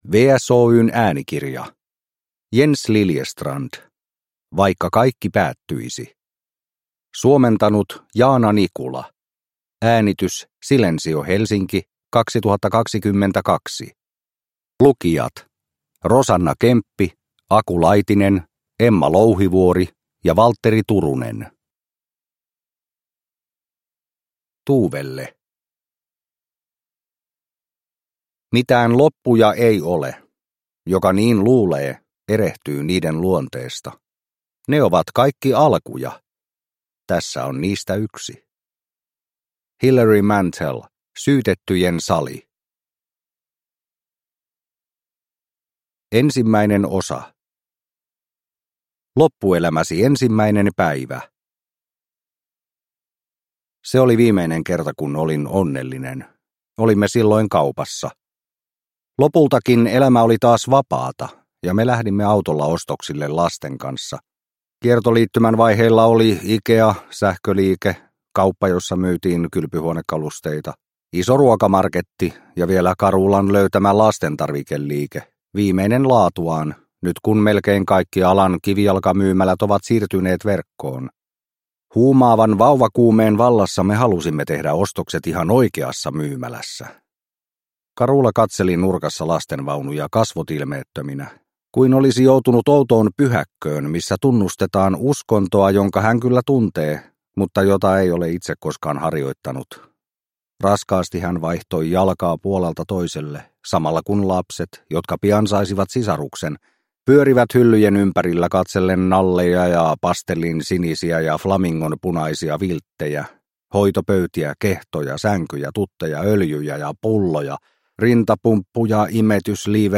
Vaikka kaikki päättyisi – Ljudbok – Laddas ner